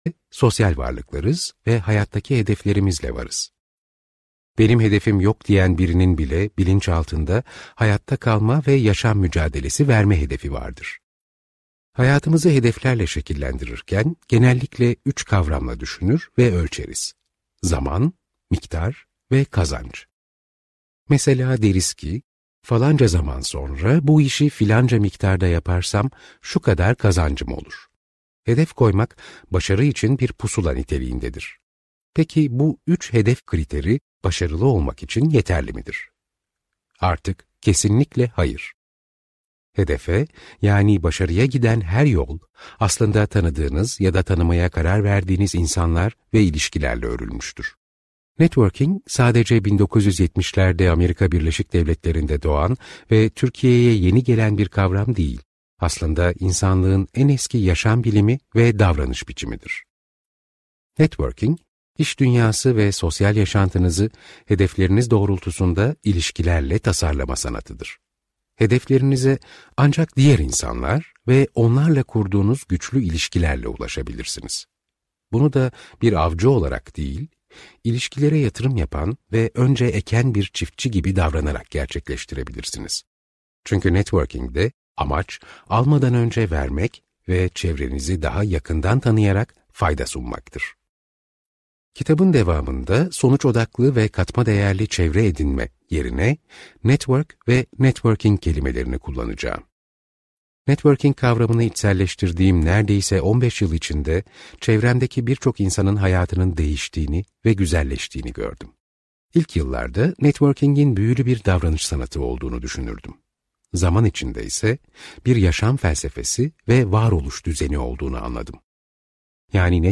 Networking - Seslenen Kitap